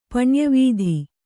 ♪ paṇya vīdhi